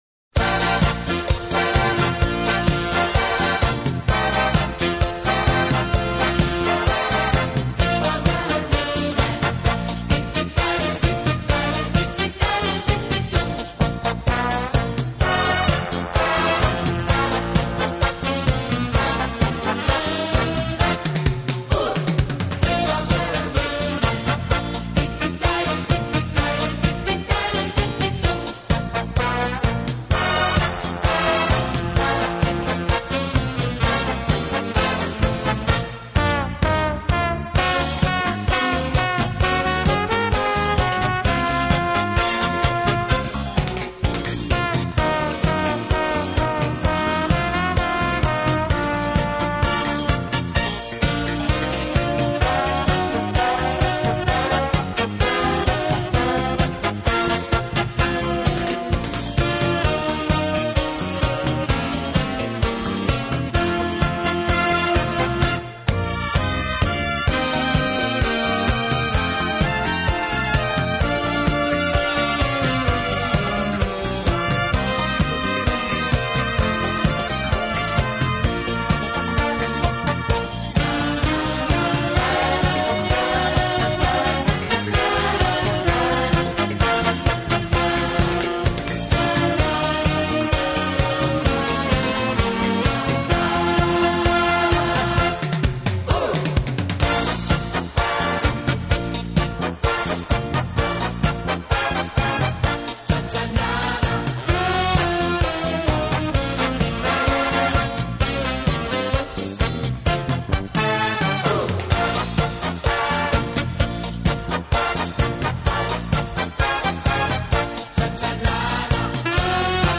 方式，尤其是人声唱颂的背景部分，似乎是屡试不爽的良药。
有动感，更有层次感；既有激情，更有浪漫。